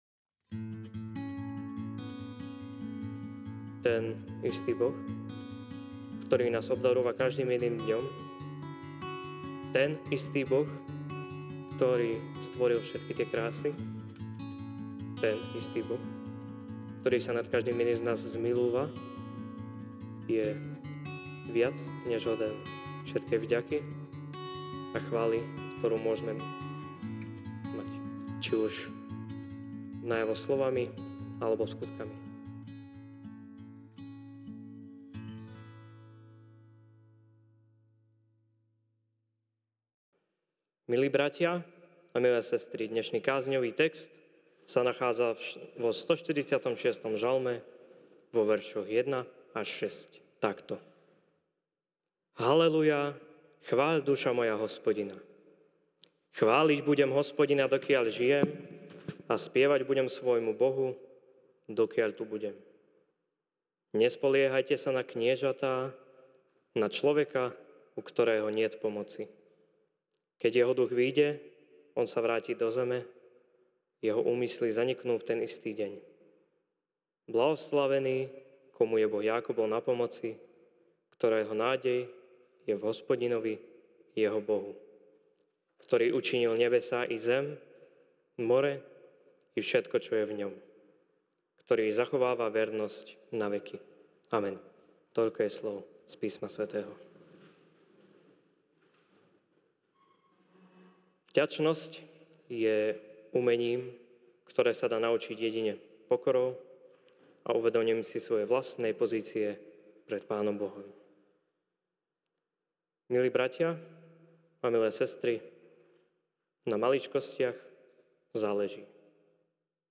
sep 01, 2024 14. nedeľa po Svätej Trojici MP3 SUBSCRIBE on iTunes(Podcast) Notes Sermons in this Series Večerná kázeň: Ž(146, 1-6) „ Haleluja!